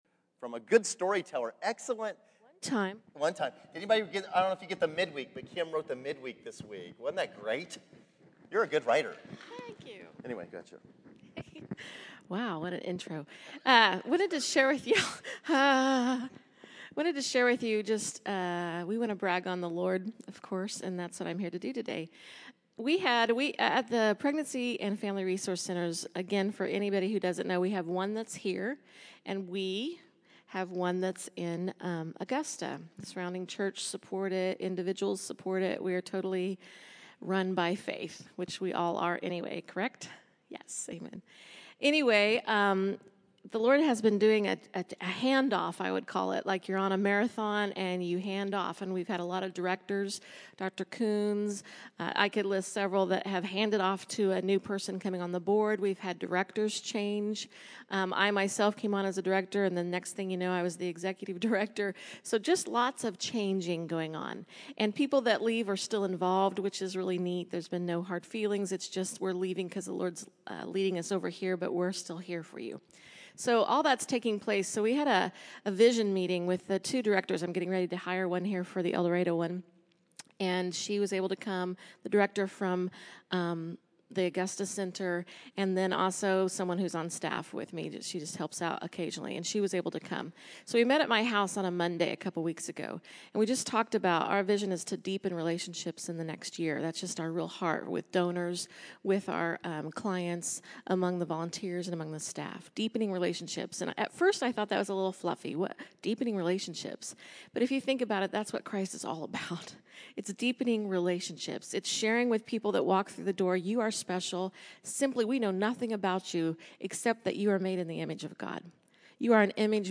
July 20, 2014      Category: Testimonies      |      Location: El Dorado